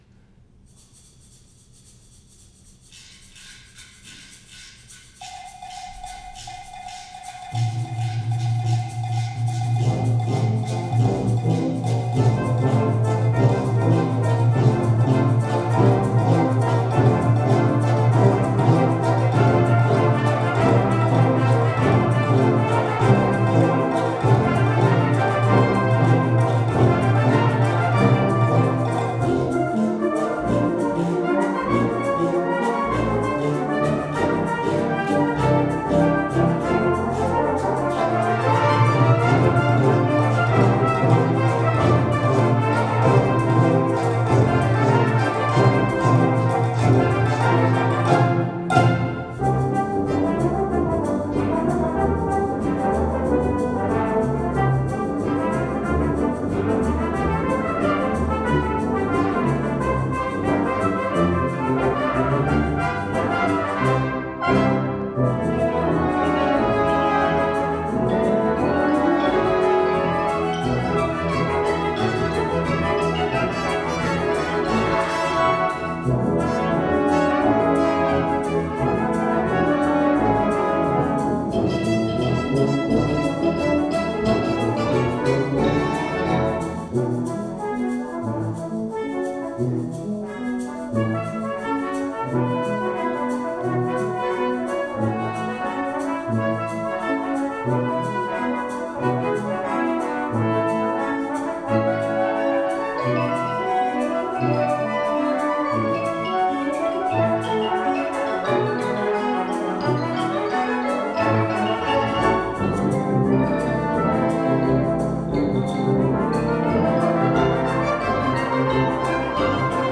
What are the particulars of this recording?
Zion Lutheran Church, Anoka, MN